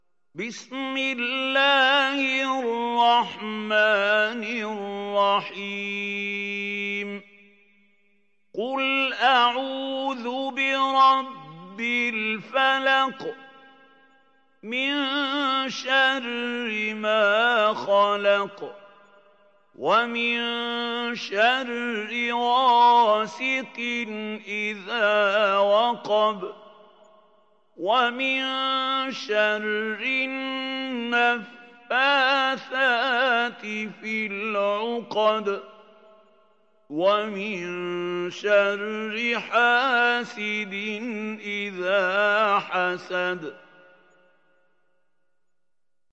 Sourate Al Falaq mp3 Télécharger Mahmoud Khalil Al Hussary (Riwayat Hafs)